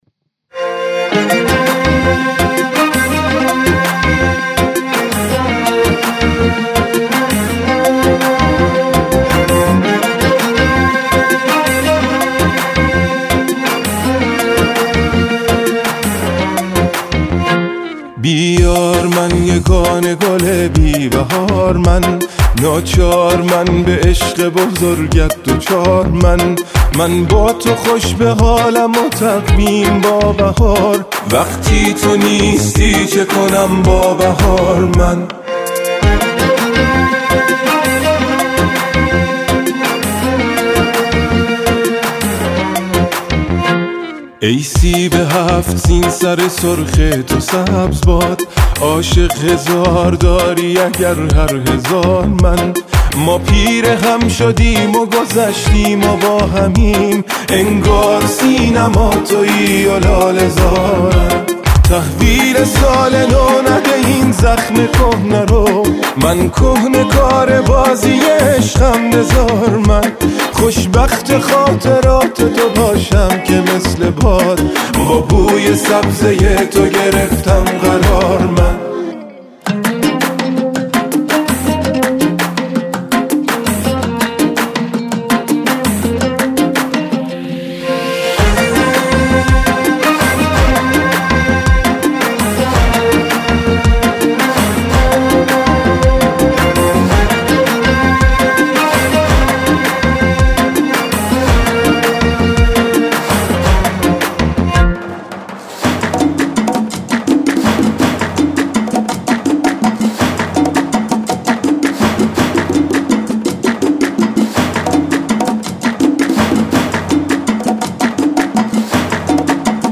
همراهی یک گروه کر اجرا شده